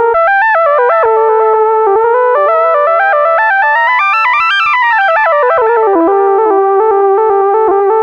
Synth 11.wav